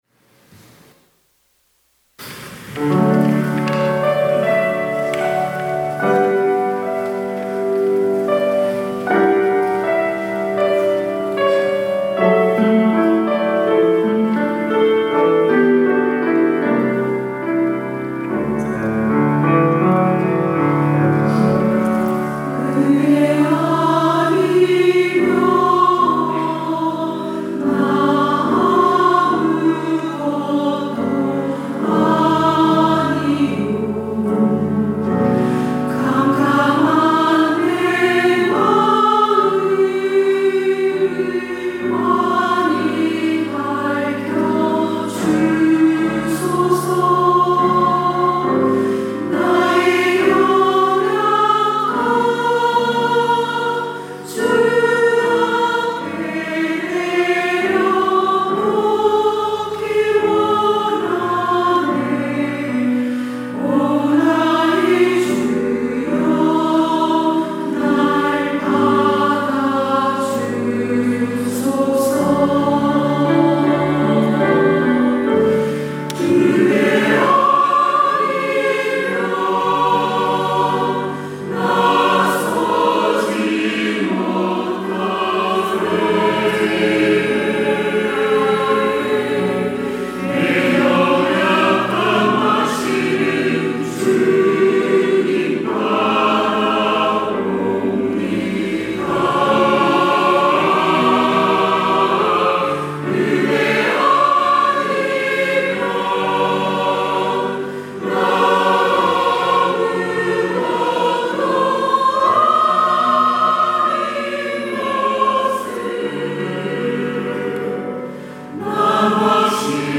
호산나(주일3부) - 은혜 아니면
찬양대